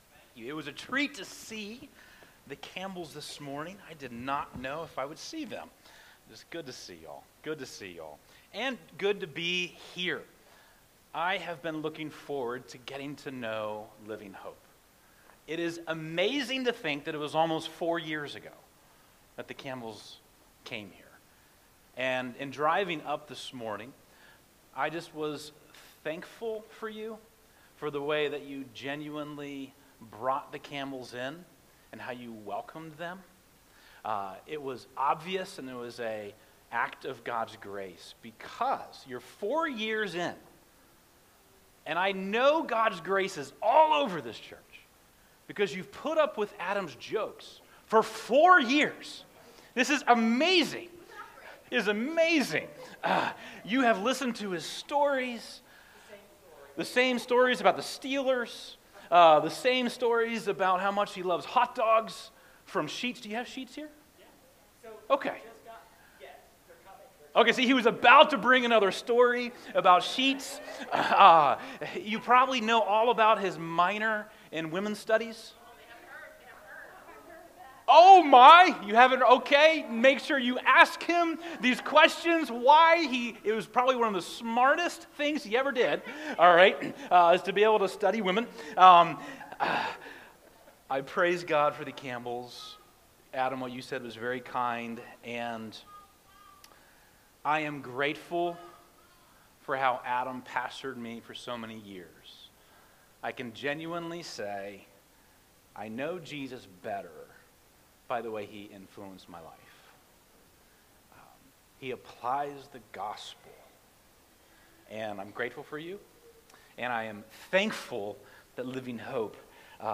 » Sermons